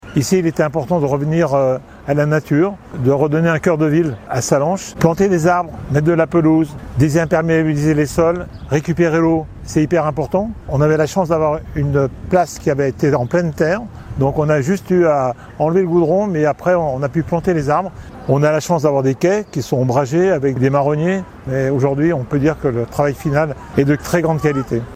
ITC Georges Morand-Inauguration Place Charles Albert.mp3